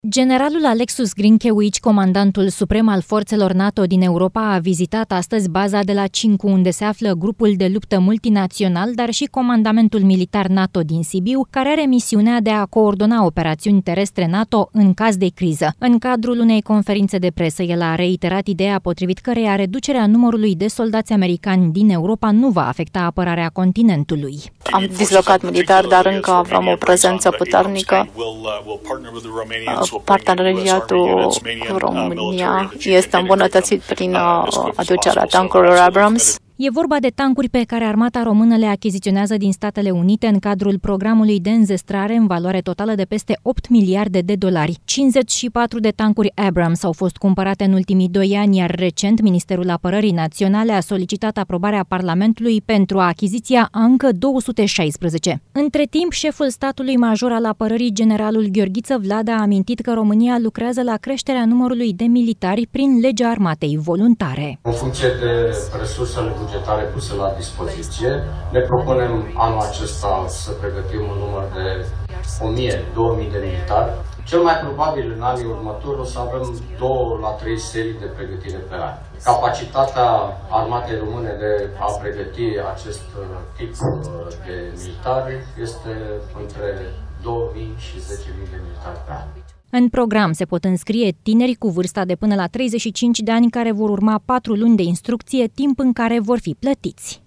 În cadrul unei conferințe de presă, el a reiterat ideea potrivit căreia reducerea numărului de soldaţi americani din Europa nu va afecta apărarea continentului.